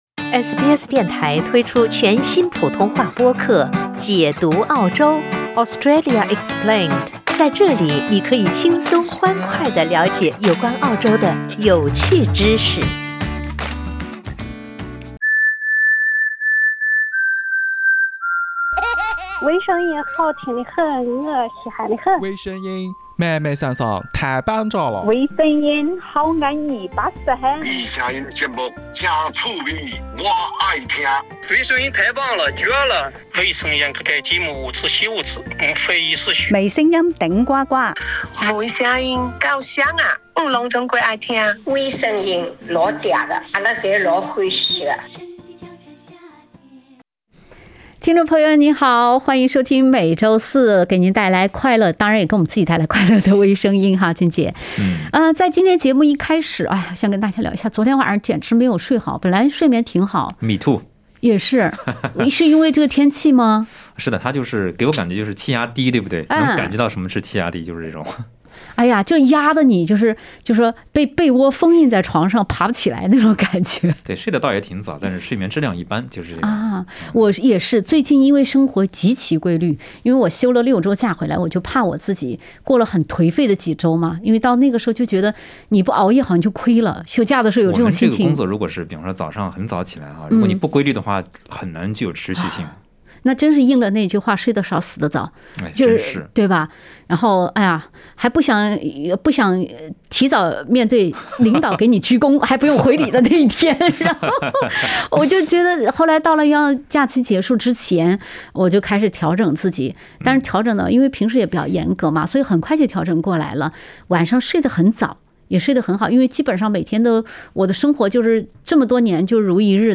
（点击封面图片，收听欢乐对话）